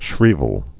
(shrēvəl)